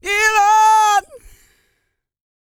E-GOSPEL 101.wav